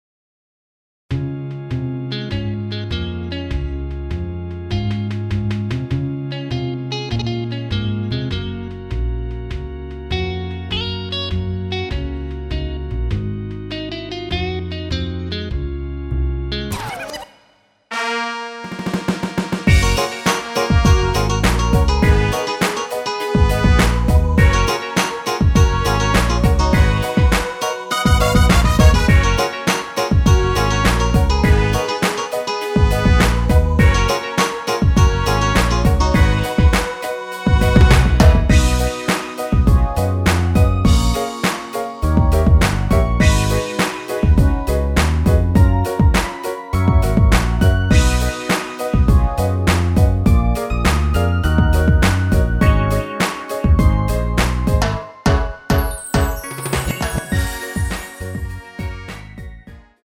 원키 랩부분 삭제한 MR입니다.(미리듣기및 본문 가사참조)
F#
앞부분30초, 뒷부분30초씩 편집해서 올려 드리고 있습니다.